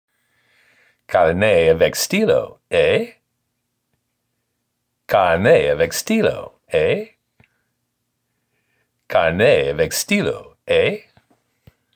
French-Canadian